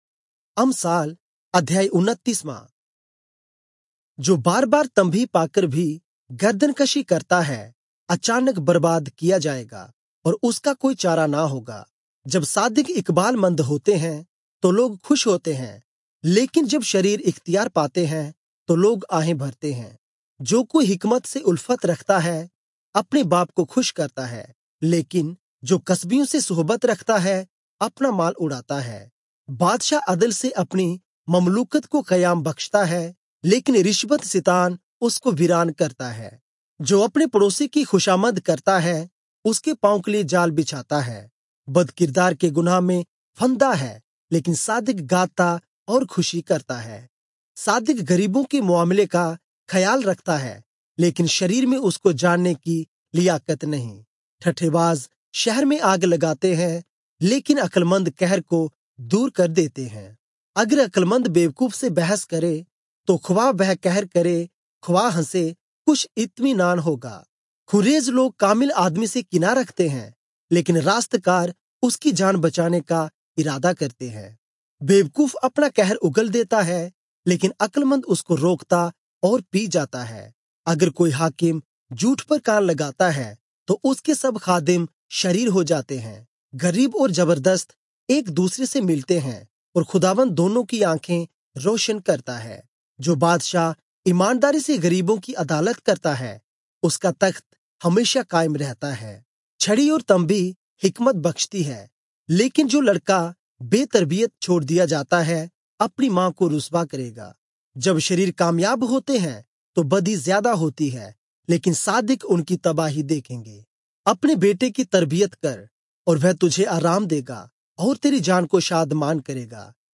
Urdu Audio Bible - Proverbs 8 in Irvur bible version